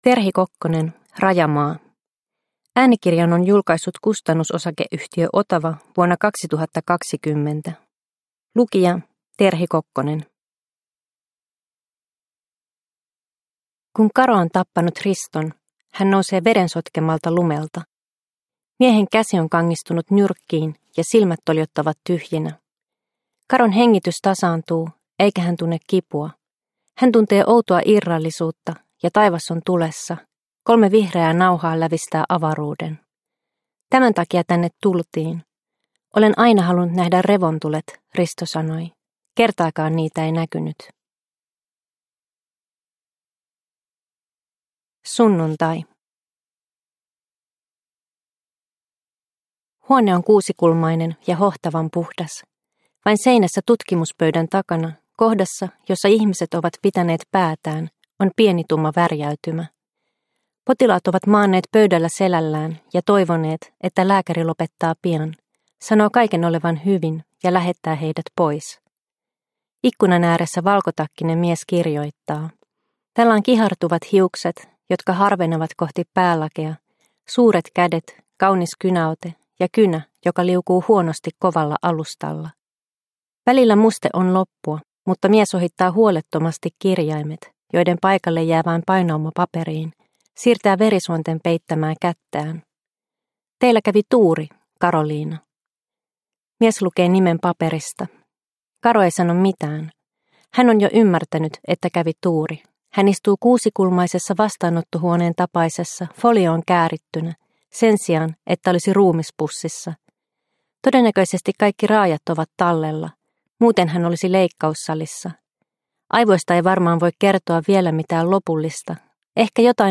Rajamaa – Ljudbok – Laddas ner